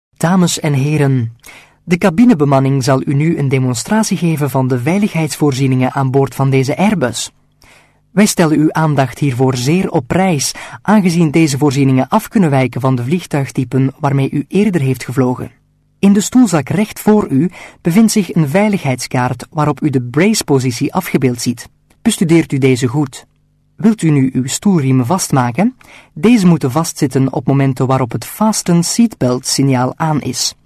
Flemish, Male, 20s-30s